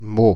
Meaux (French pronunciation: [mo]
Fr-Paris--Meaux.ogg.mp3